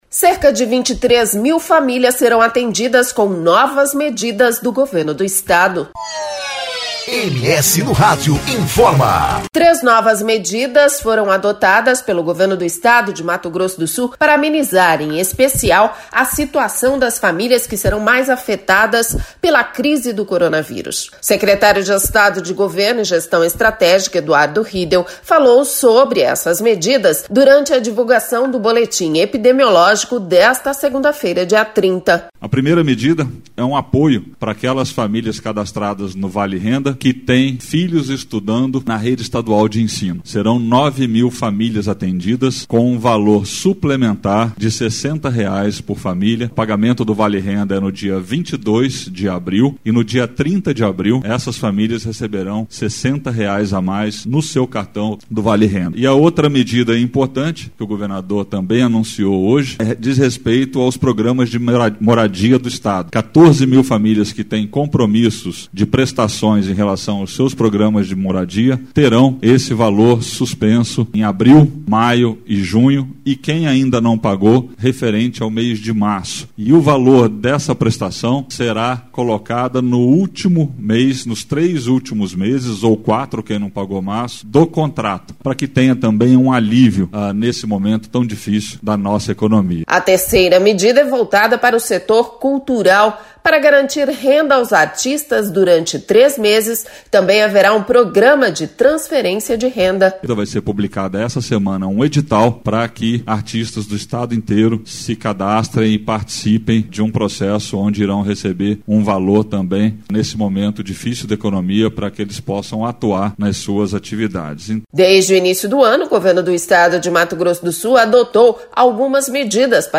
O secretário de Estado de Governo e Gestão Estratégica Eduardo Riedel falou sobre durante a divulgação do boletim epidemiológico desta segunda-feira, dia 30.